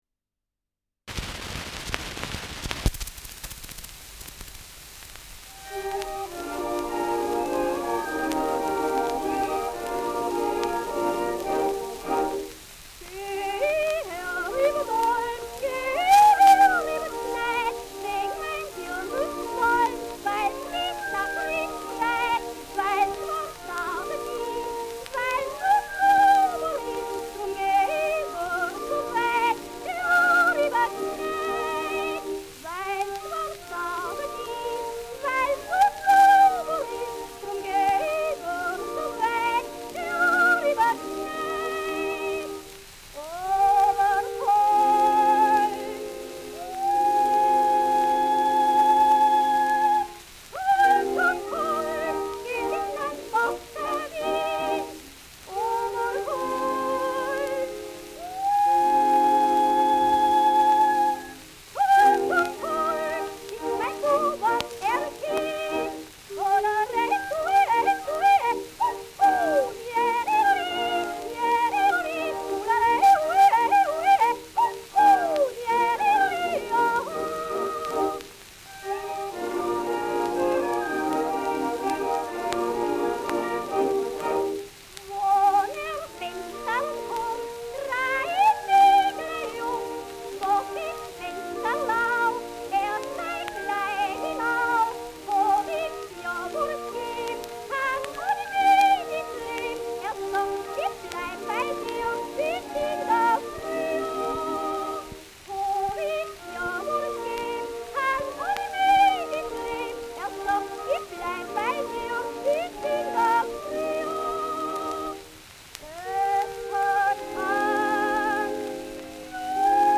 Schellackplatte
German Yodle Song : with Orchestra
mit Orchesterbegleitung
[Wien] (Aufnahmeort)